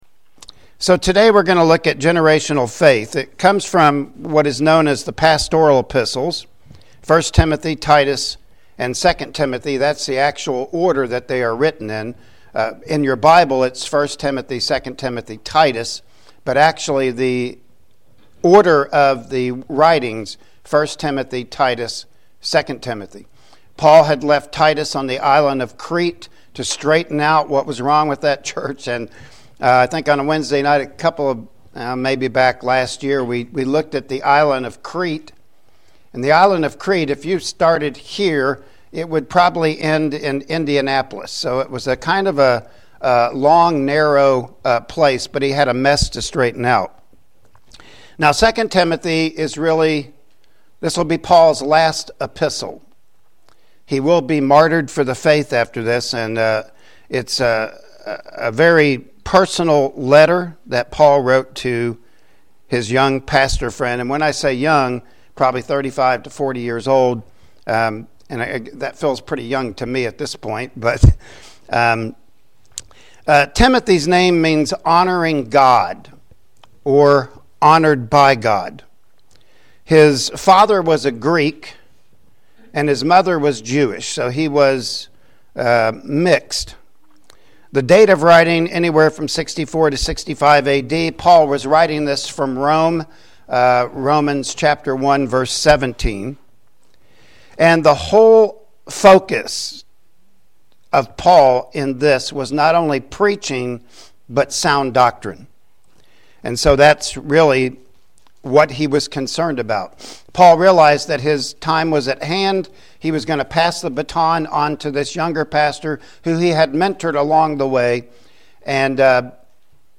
2 Timothy 1:5-7 Service Type: Sunday Morning Worship Service Topics